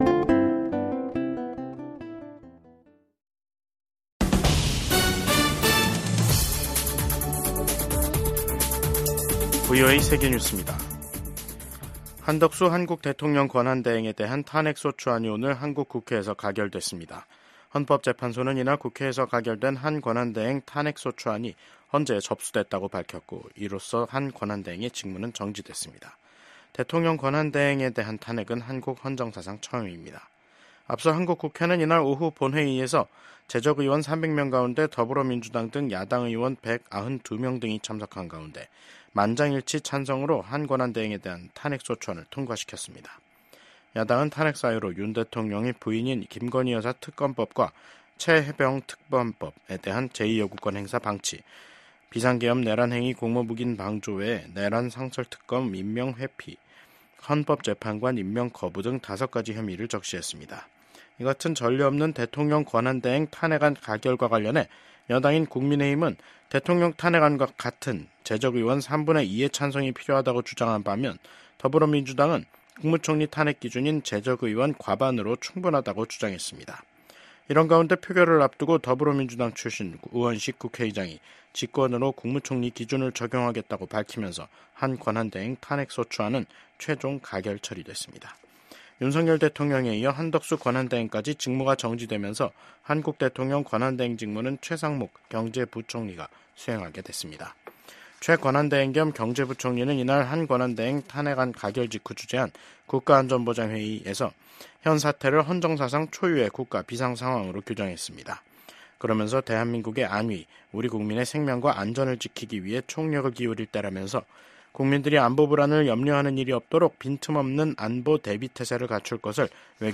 VOA 한국어 간판 뉴스 프로그램 '뉴스 투데이', 2024년 12월 27일 2부 방송입니다. 한덕수 한국 대통령 권한대행 국무총리의 탄핵 소추안이 국회에서 가결됐습니다. 한국 국가정보원은 북한 군 1명이 러시아 쿠르스크 전장에서 생포됐다는 우크라이나 매체 보도에 대해 사실이라고 밝혔습니다. 미국 전문가들은 역내 안보환경을 고려할 때 미한일 협력의 필요성이 여전히 높다고 평가했습니다.